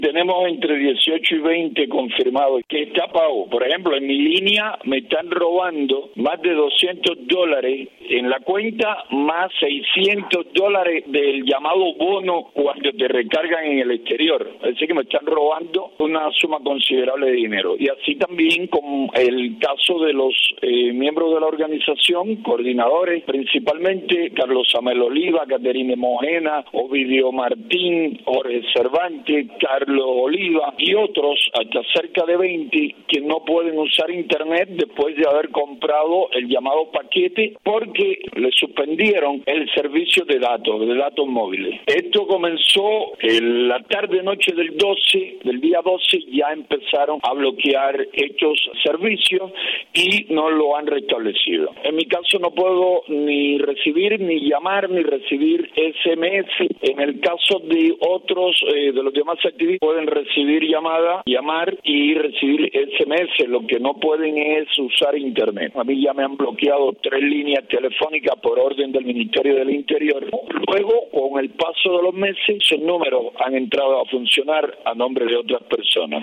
Declaraciones de José Daniel Ferrer a Radio Martí